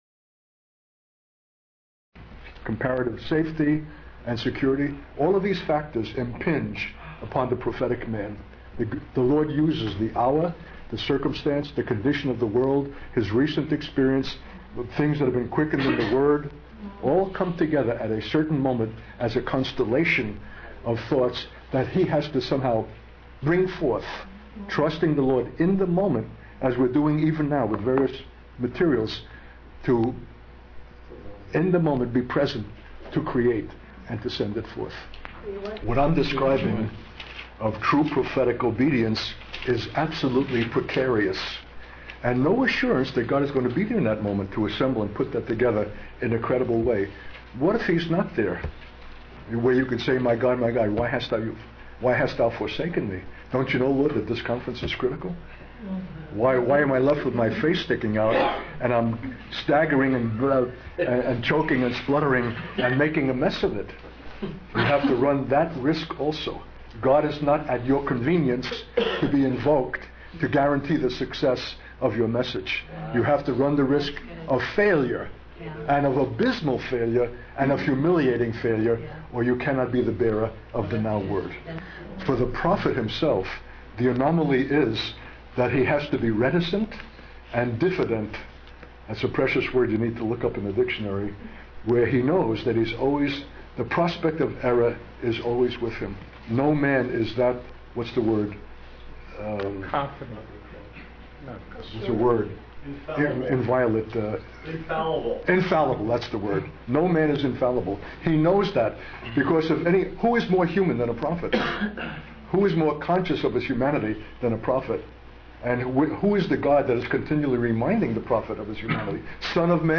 In this sermon, the speaker describes a challenging moment where he is about to deliver the concluding message but faces numerous obstacles.